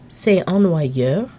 Click each phrase to hear the pronunciation.